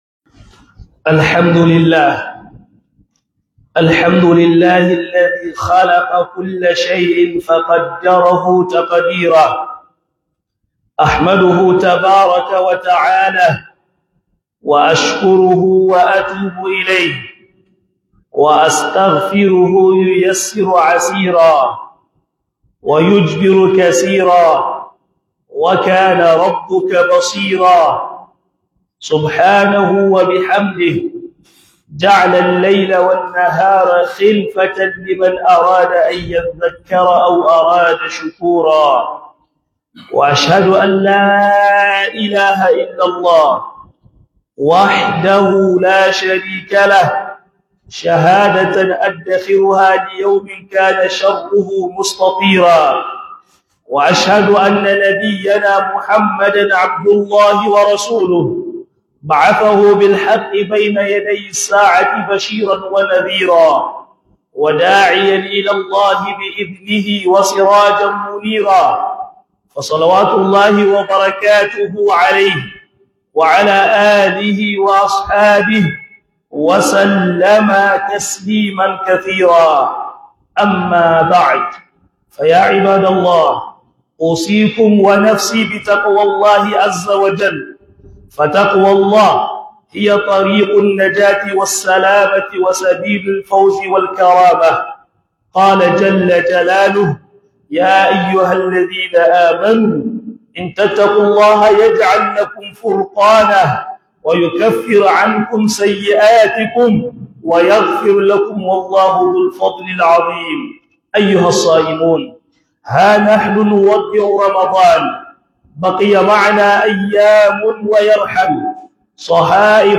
Khutubar Juma'a Mai Taken Ramadan Yana Bankoina - HUDUBA
Khutubar Juma'a Mai Taken Ramadan Yana Bankoina